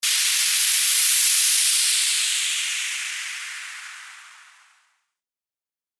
FX-1486-WHOOSH
FX-1486-WHOOSH.mp3